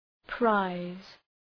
Προφορά
{praız}
prize.mp3